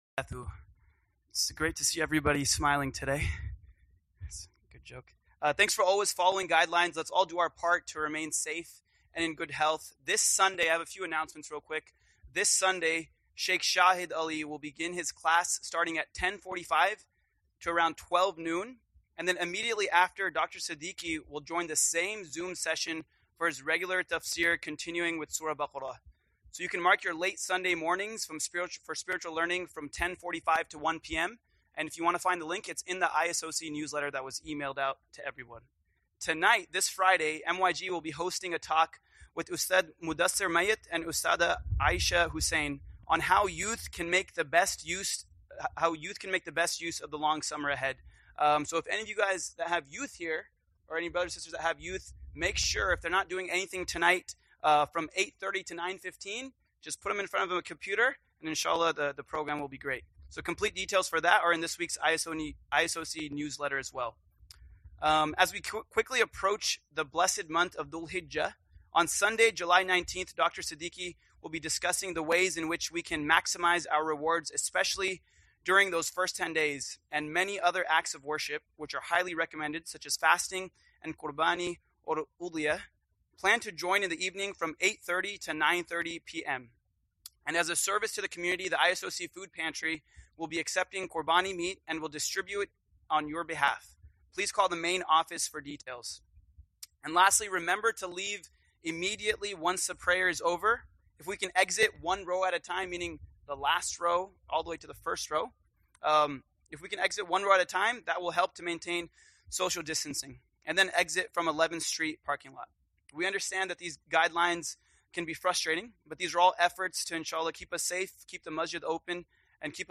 Friday Khutbah - "Signs and Changes"